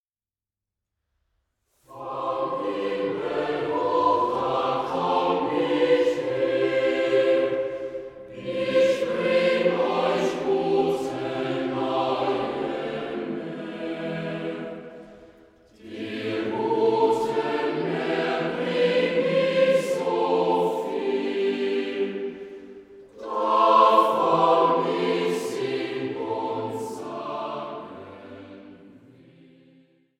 Weihnachtsliedern